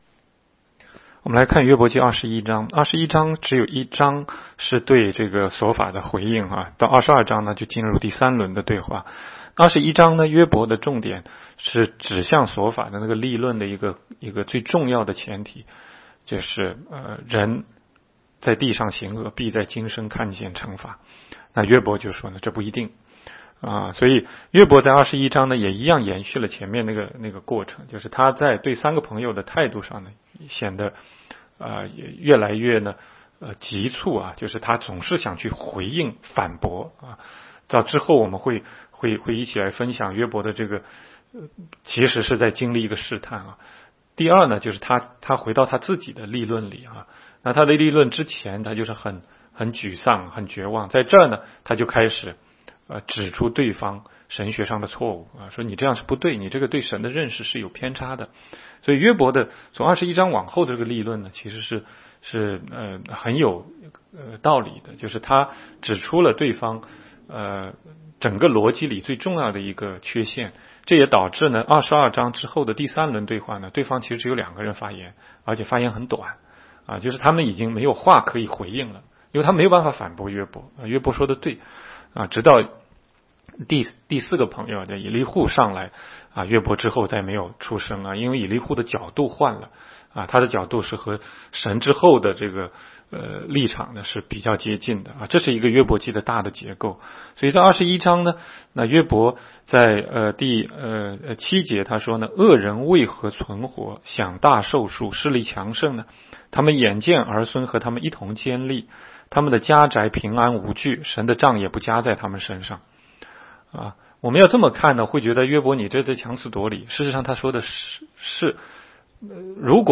16街讲道录音 - 每日读经-《约伯记》21章